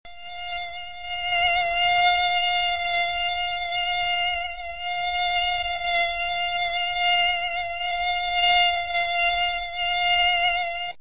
На этой странице собраны разнообразные звуки мошек: от тихого жужжания до назойливого писка.
Звук жужжания мошки возле уха